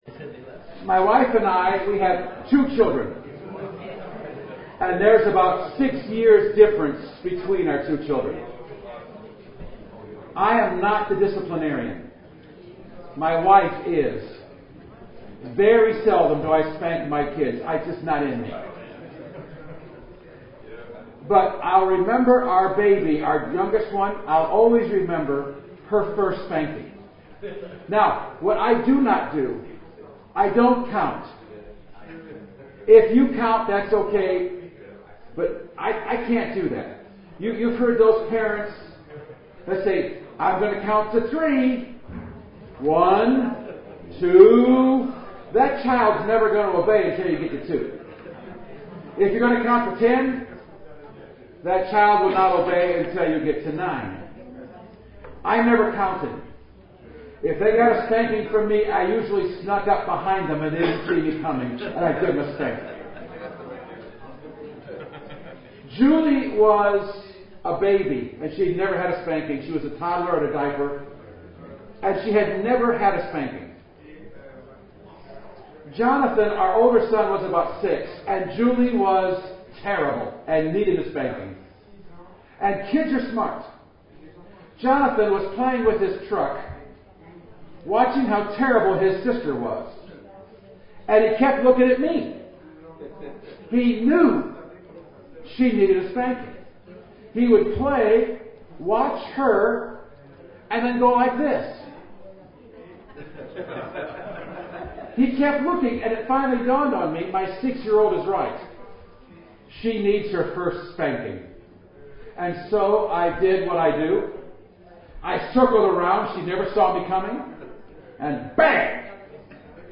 This audio is from session three of the EuNC Leadership Conference 2014 and focuses on small group discipleship in the church with examples from the Valparaiso Church of the Nazarene in Indiana, USA.